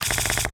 sfx_gacha 02.wav